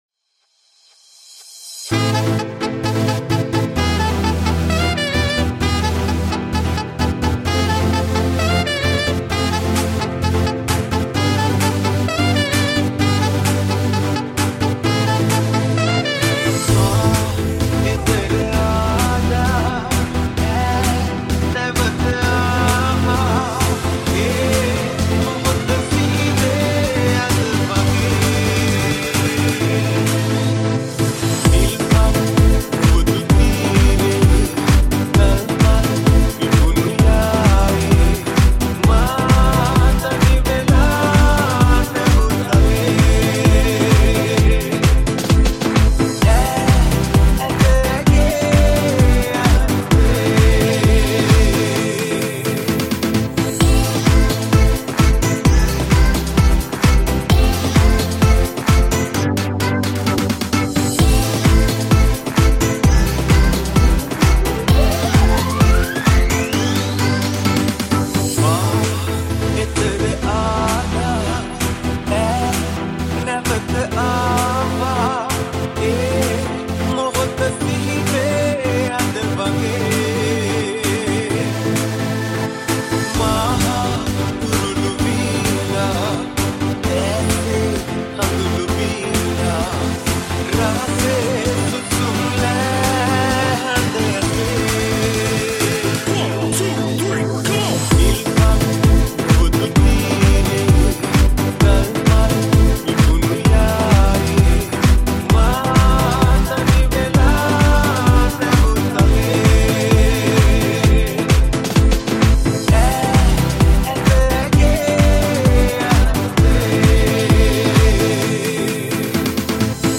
Dance & EDM